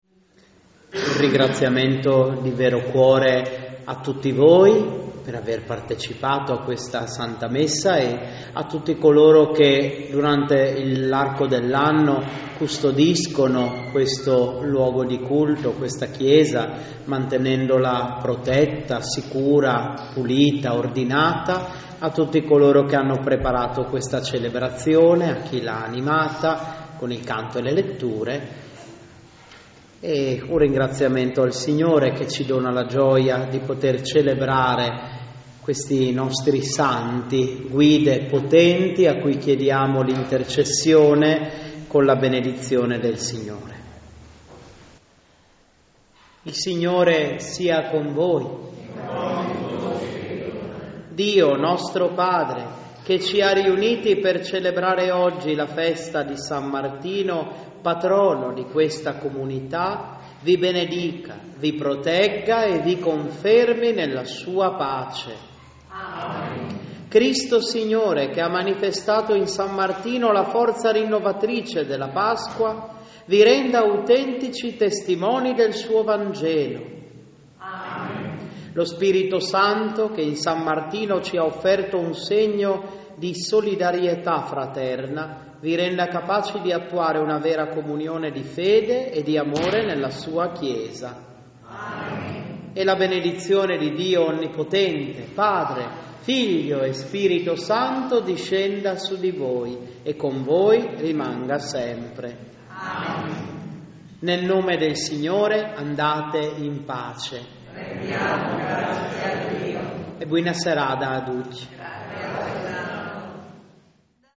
Festa Patronale di San Martino